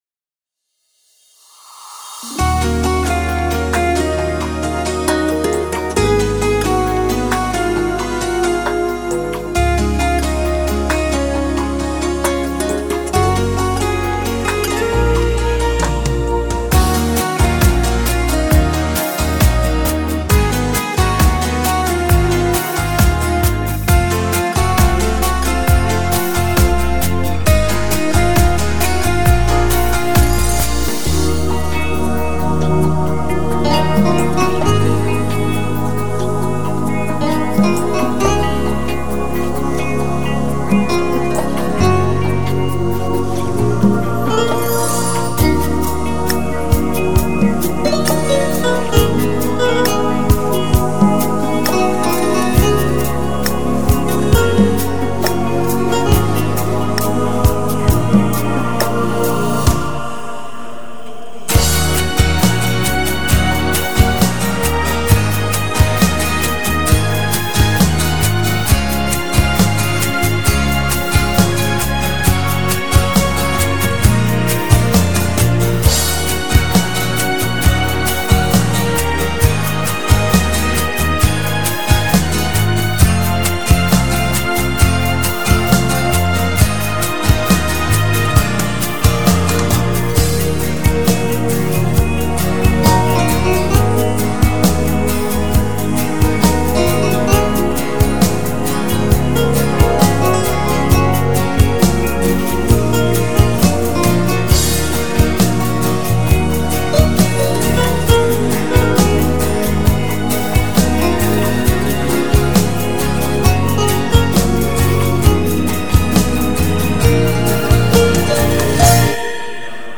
Часто используемые минусовки хорошего качества